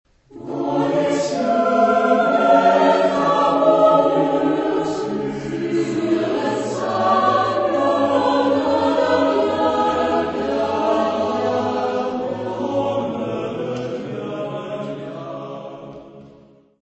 Genre-Style-Form: Secular ; Choral suite
Mood of the piece: modern ; humorous
Type of Choir: SSAATTBBB  (9 mixed voices )
Tonality: polytonal